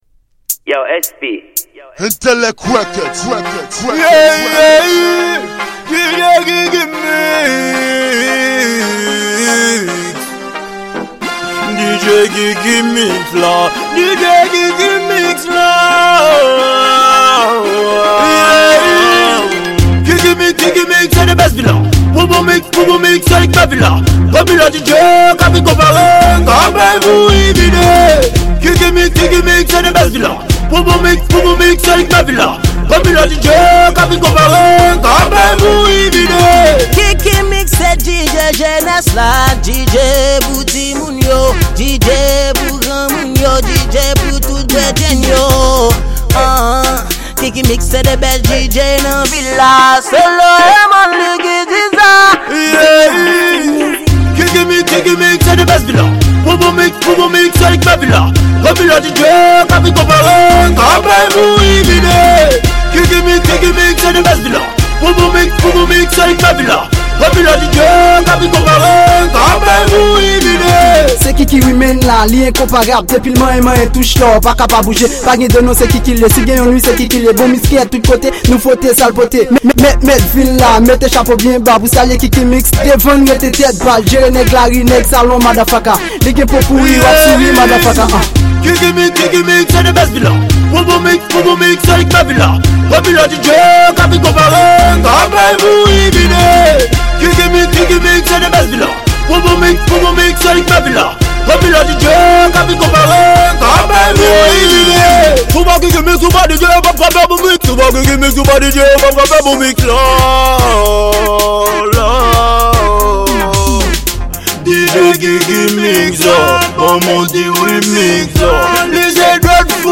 Genre: Riddim.